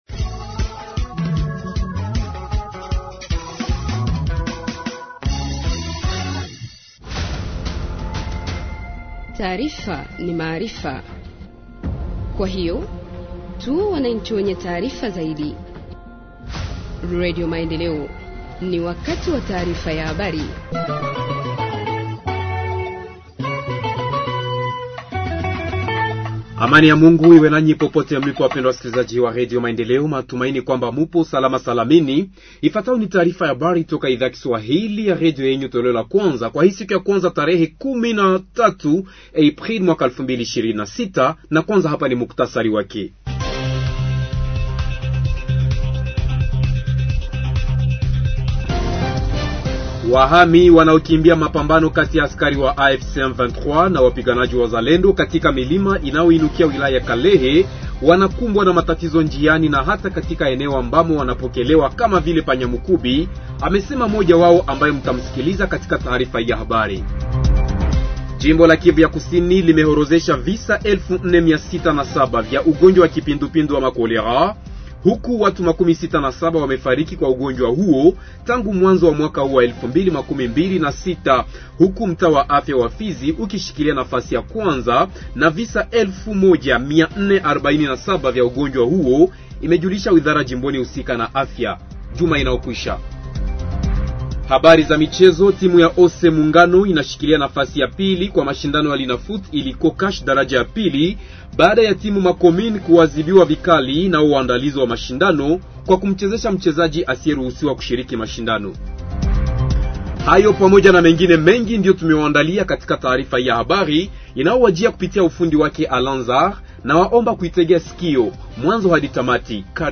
Journal en Swahili du 13 Avril 2026 – Radio Maendeleo